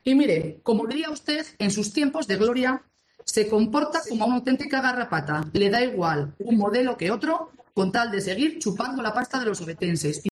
Tanto PSOE como Somos han vuelto a escenificar en el pleno ordinario celebrado este martes en el Ayuntamiento de Oviedo sus quejas y "pataletas" por esta cuestión.
No se ha mordido la lengua la concejala de Festejos, Covadonga Díaz, que ha llamado garrapata al portavoz de Somos, del que dice solo le interesa la pasta.